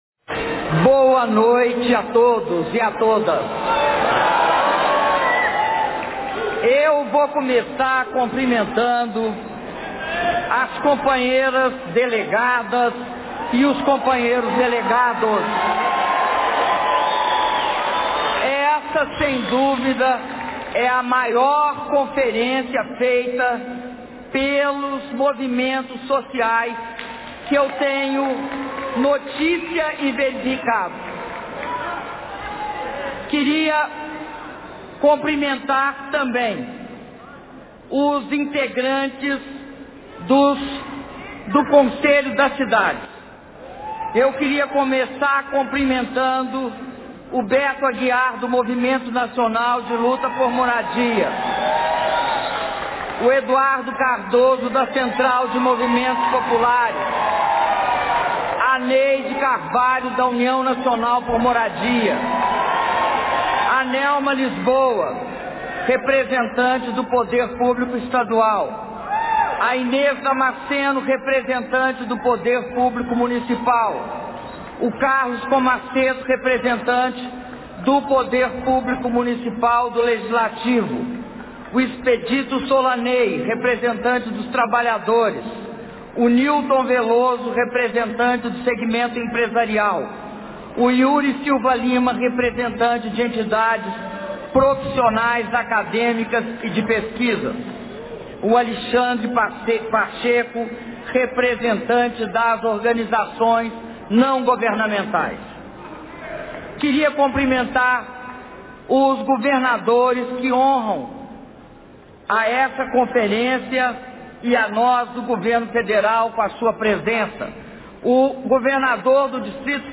Áudio do discurso da Presidenta da República, Dilma Rousseff, na cerimônia de abertura da 5ª Conferência Nacional das Cidades - Brasília/DF